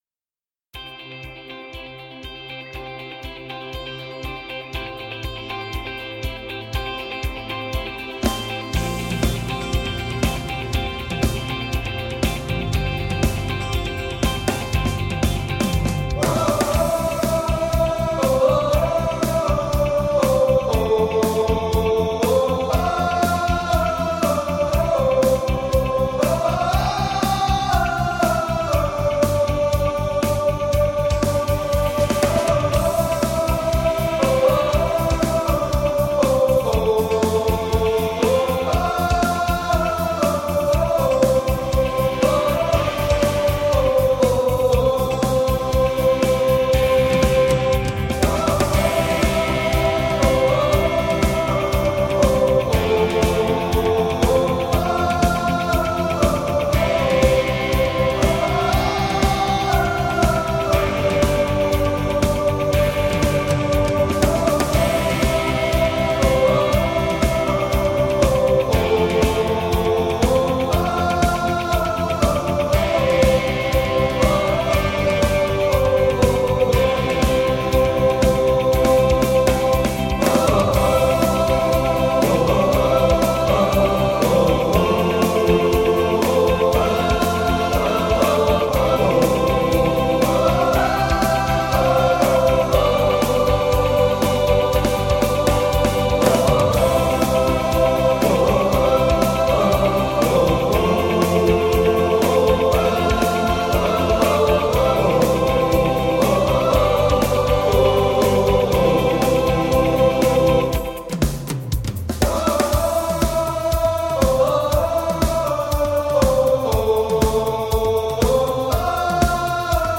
Titel ' We Are The Winners ' ➞ Alle Titel ' Gewinner-Stimmung ist Party-Stimmung.